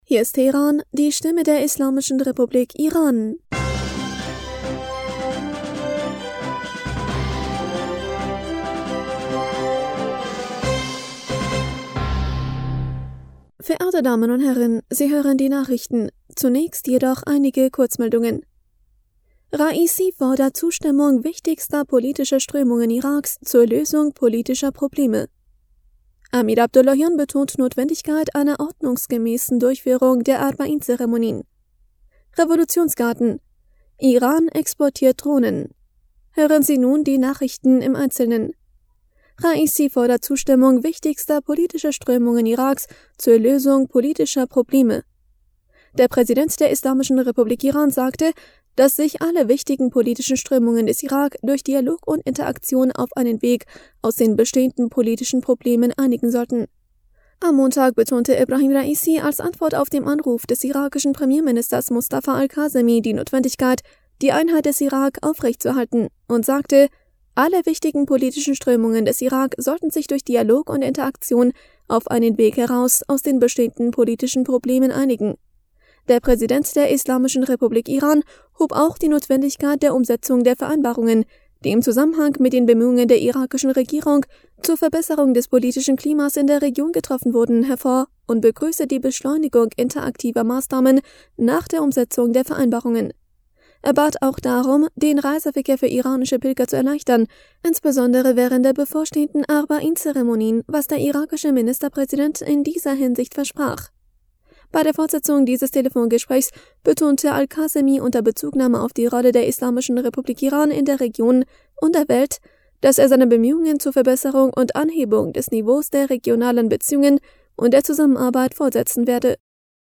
Nachrichten vom 23. August 2022
Die Nachrichten von Dienstag, dem 23. August 2022